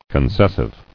[con·ces·sive]